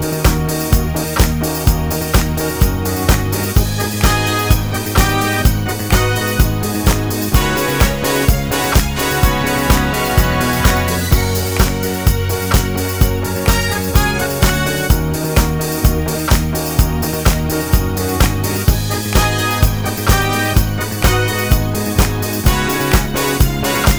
no Backing Vocals Disco 3:44 Buy £1.50